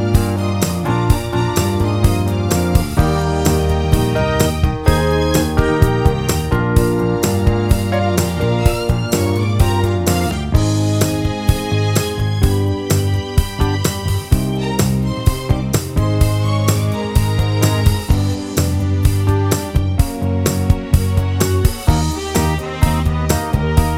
no guitars with Backing Vocals For Guitarists 5:17 Buy £1.50